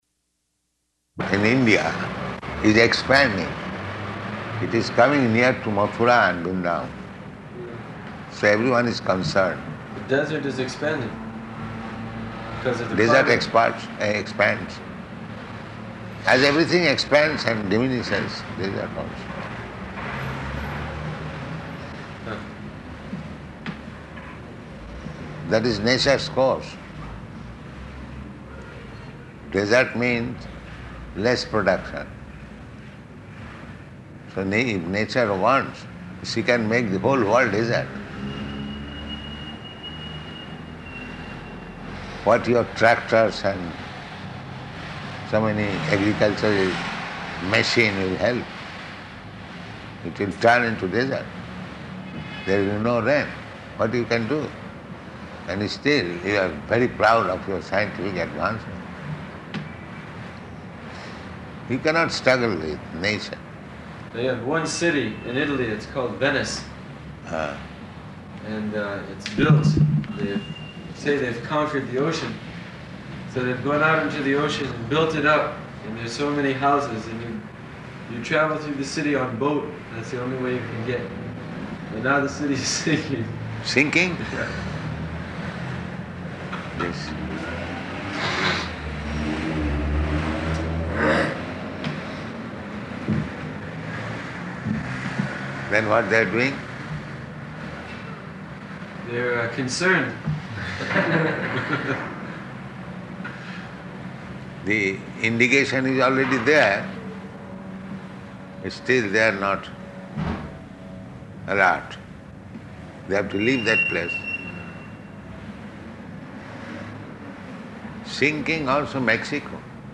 Room Conversation
-- Type: Conversation Dated: August 10th 1973 Location: Paris Audio file